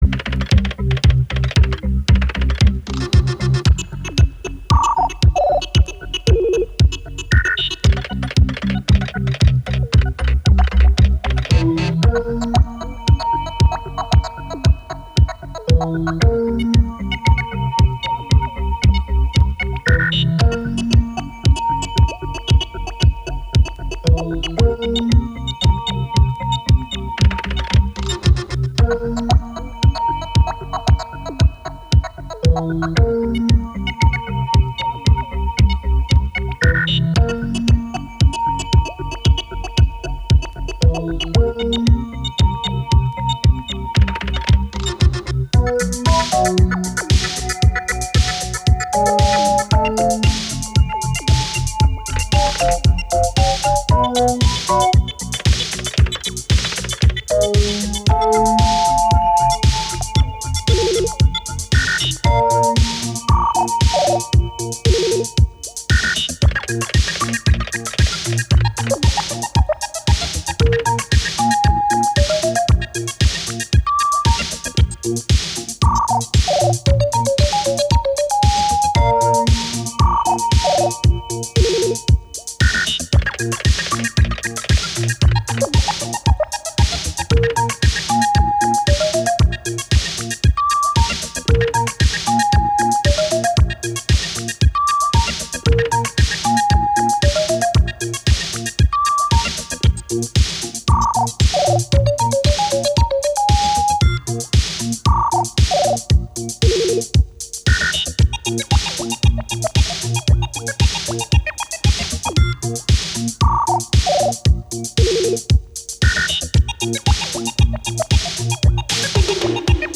Вырезано из мегамикса.